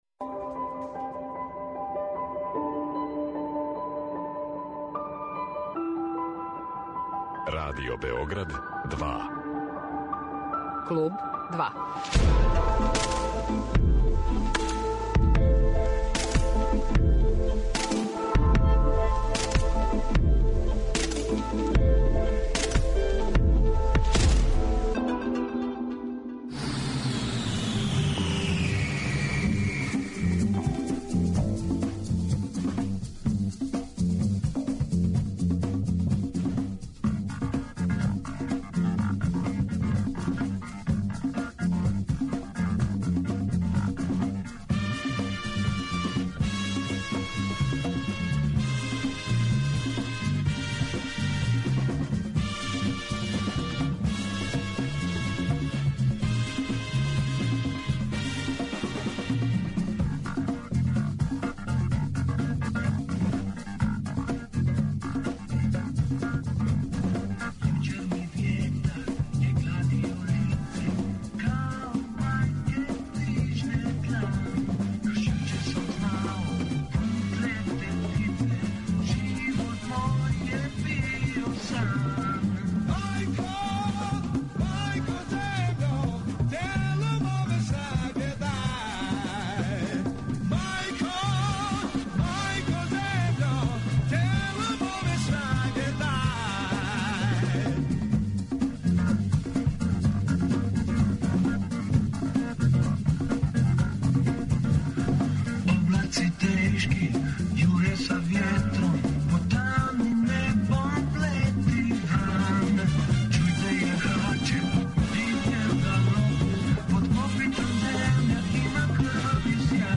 Гост Kлуба 2 је Тихомир Поп Асановић
У данашњој емисији подсетићемо се разговора са Тихомиром Поп Асановићем. Провешћемо вас кроз историју фанка на просторима бивше Југославије.